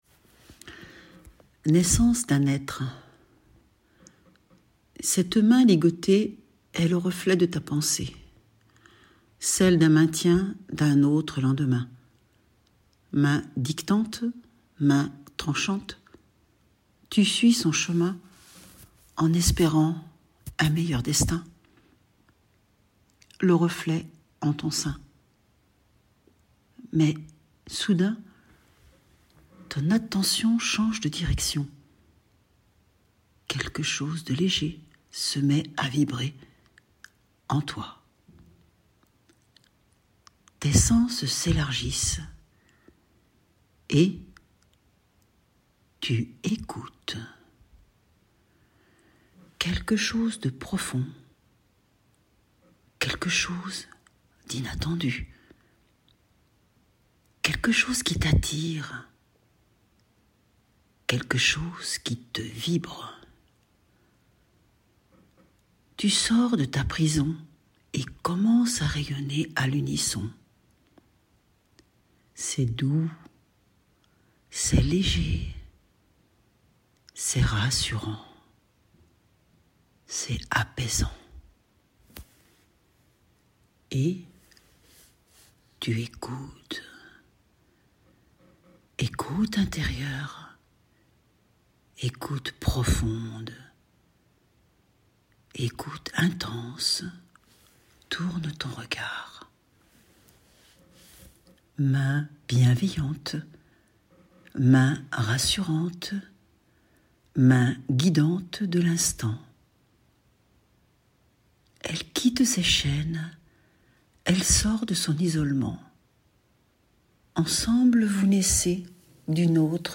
POEME  EN   AUDIO